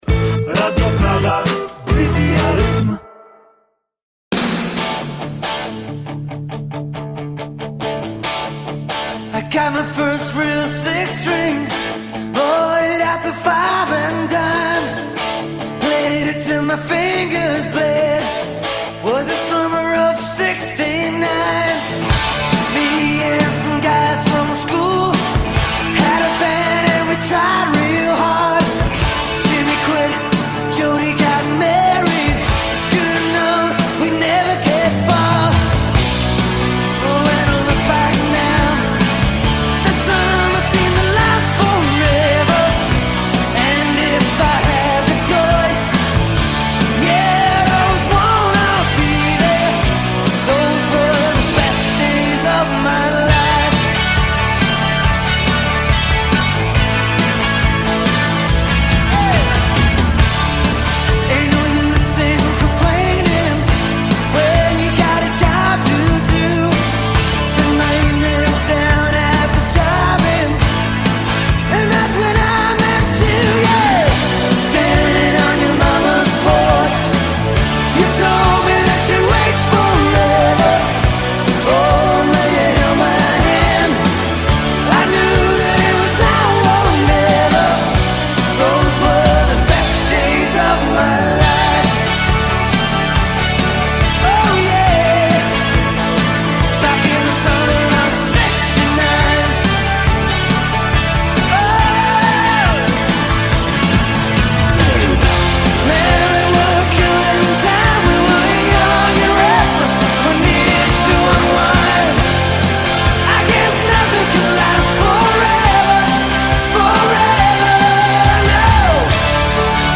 W odcinku jest trochę śmiechu, trochę wspomnień i kilka historii, których nie znajdziecie w żadnym przewodniku.